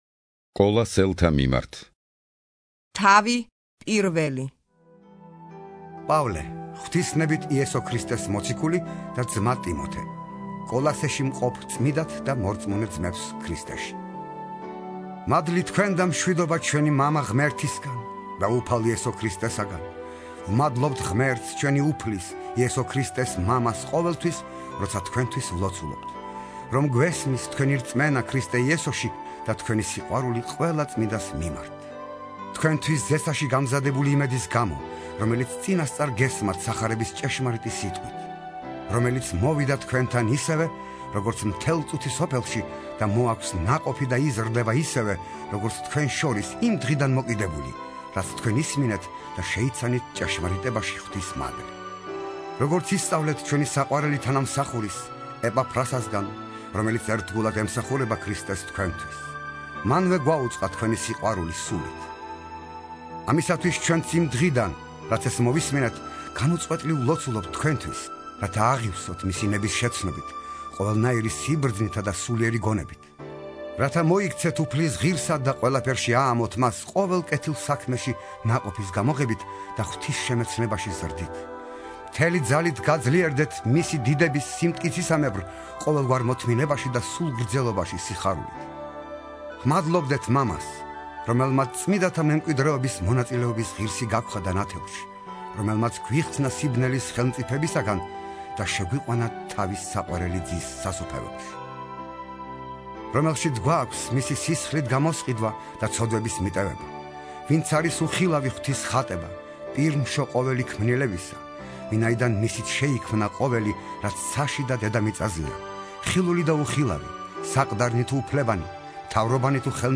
(51) ინსცენირებული ახალი აღთქმა - პავლეს ეპისტოლენი - კოლასელთა მიმართ